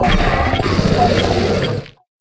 Cri de Têtes-de-Fer dans Pokémon HOME.